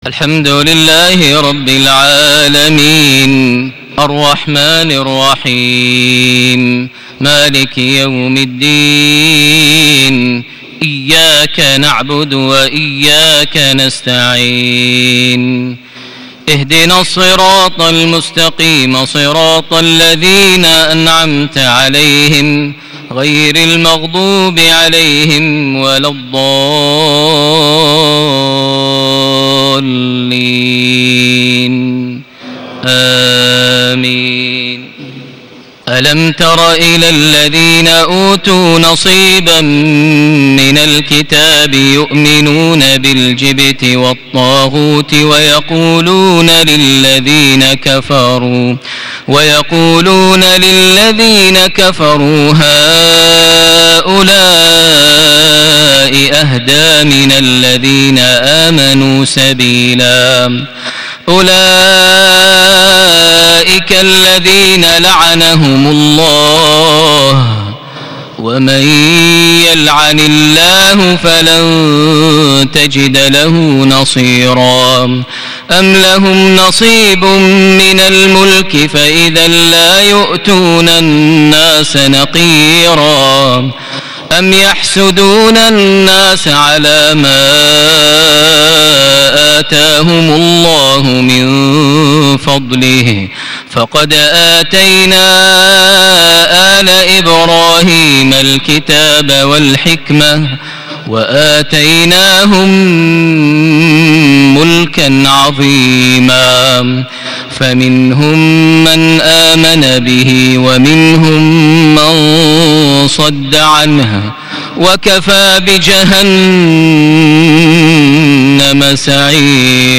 صلاة المغرب ٩ جماد الآخر ١٤٣٨هـ سورة النساء ٥١-٥٧ > 1438 هـ > الفروض - تلاوات ماهر المعيقلي